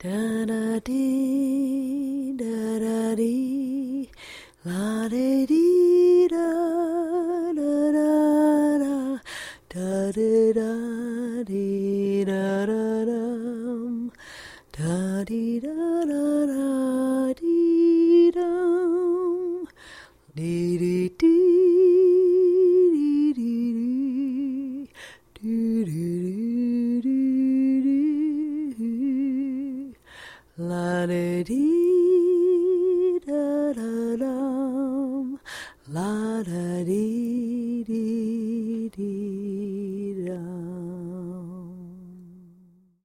Мамин голос поет нежную колыбельную укладывая ребенка спать